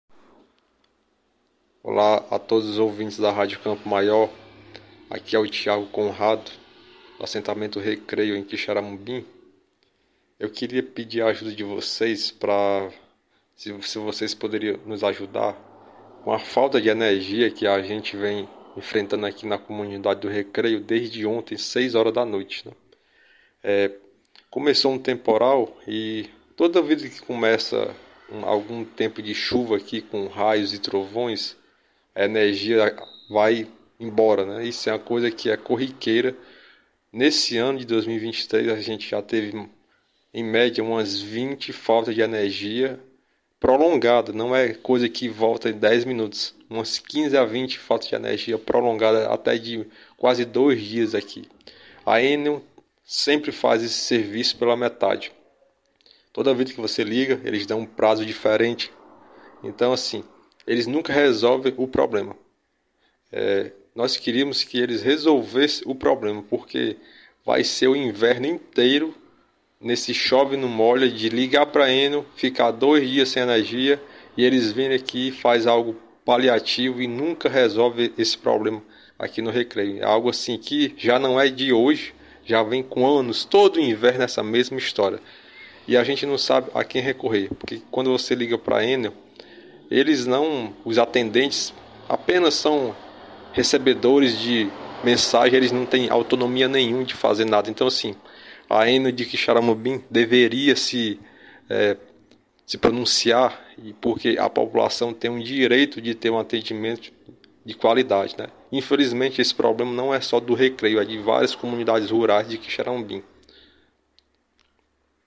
Veja o relato enviado ontem: Morador da localidade de Recreio